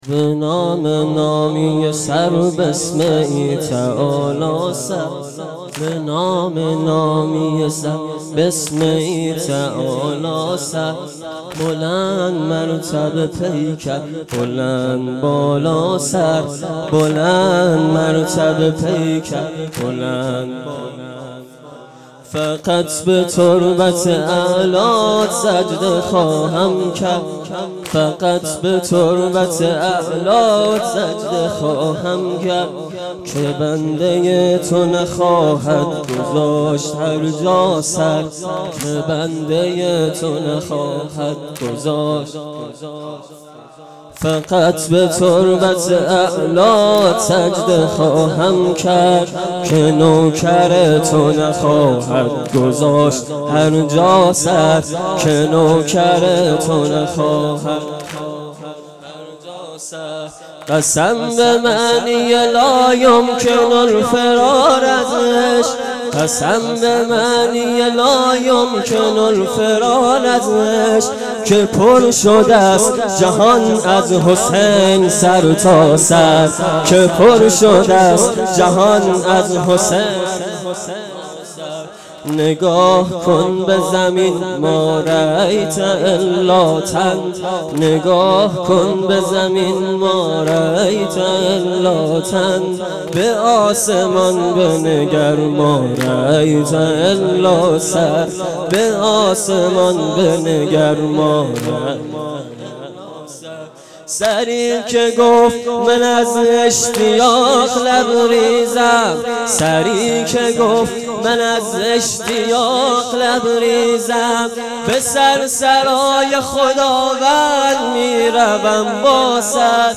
واحد تند شب یازدهم محرم